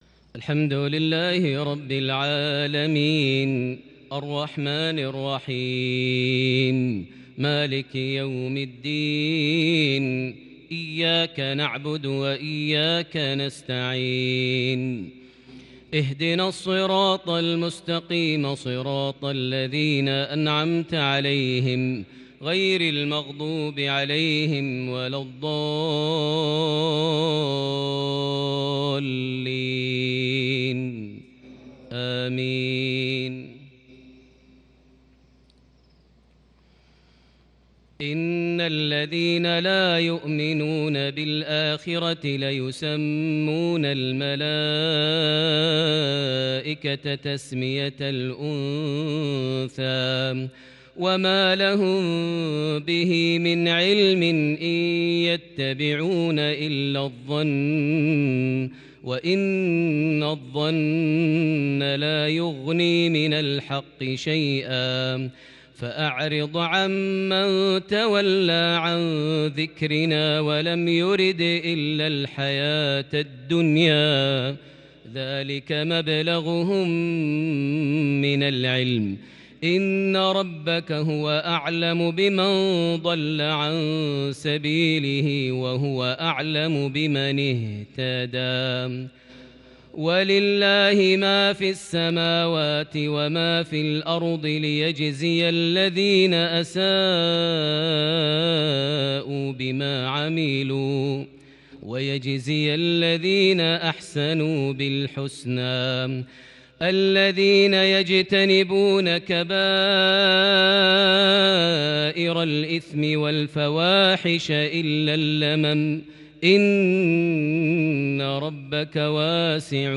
تلاوة مباركه بلمحات الكرد من سورة النجم (27-47) | مغرب 10 جمادى الآخر 1442هـ > 1442 هـ > الفروض - تلاوات ماهر المعيقلي